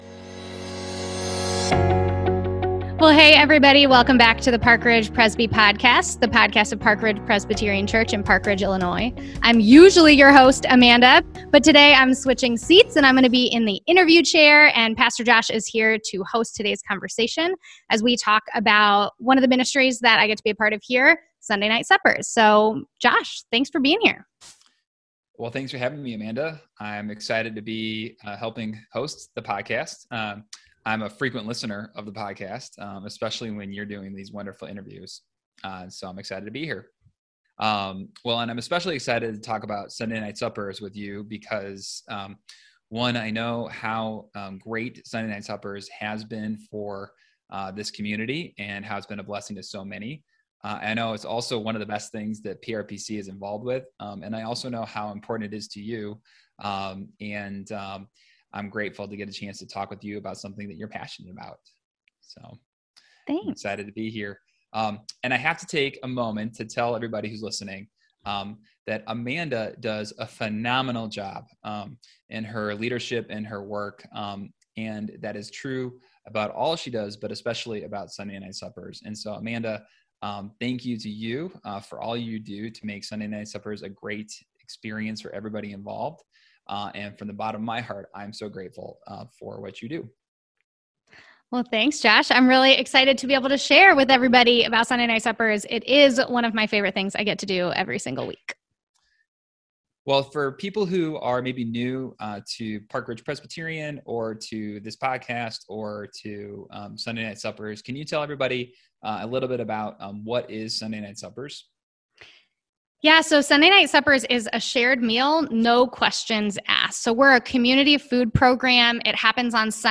upload-ready-sns-interview.mp3